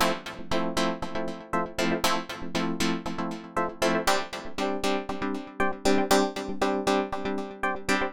28 Chords PT4.wav